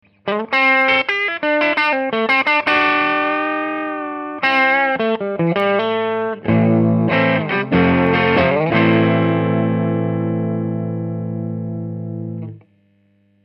meme gratte type télé, meme ampli B4 (EF184+6V6 bias 70%) sans correction de tonalité, meme position du potard de volume de l'ampli, meme micro, a la meme position/distance... il n'y a pas de normalisation des samples, juste une conversion en MP3 codec LAME qui compresse un peu j'en suis désolé.
sample 3 - transfo ESO SE 5W 5k-8r primaire 4H
Le ESO est plus puissant et à plus de basses, mais on ne peut pas dire que les autres sons ne sont pas exploitables, pour un ampli basse je prendrais le ESO, mais pour un ampli guitare très clean je prendrais plutôt le 1er ou le 2ème, on voit bien que ça limite les basses.